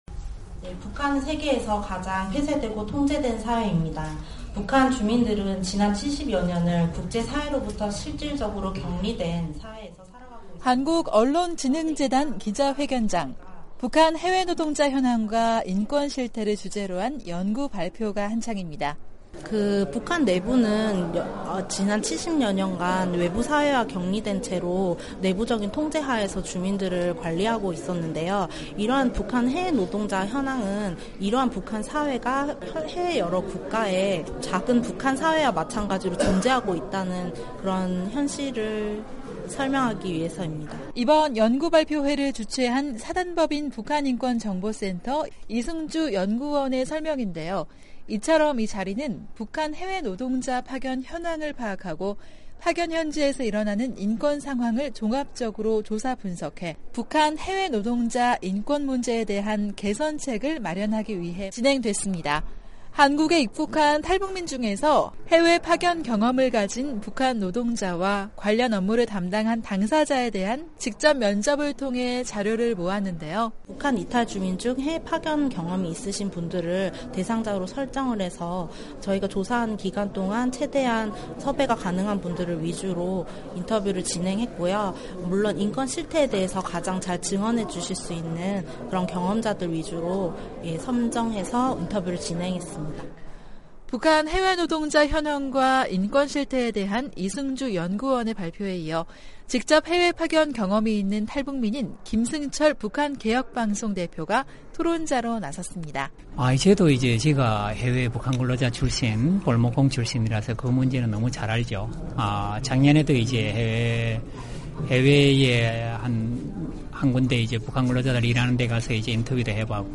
기자가 현장을 취재했습니다.